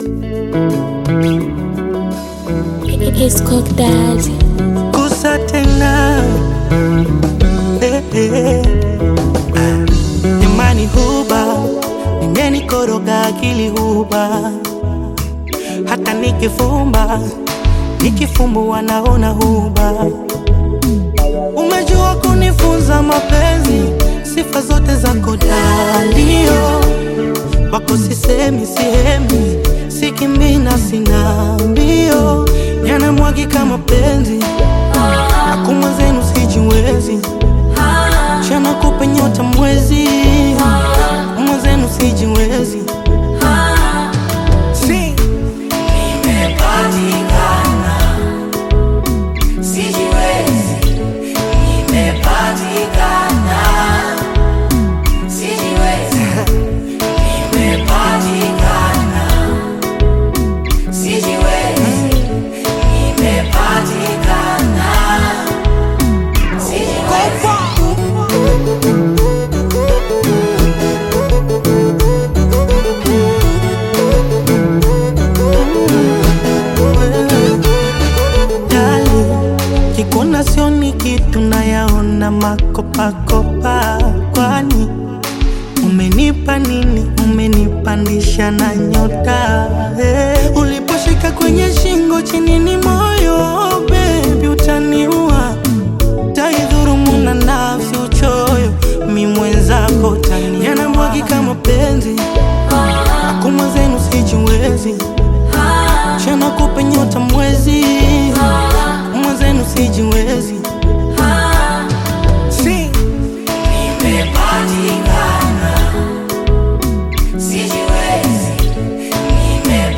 Bongo Flava music track
Tanzanian Bongo Flava artist, singer, and songwriter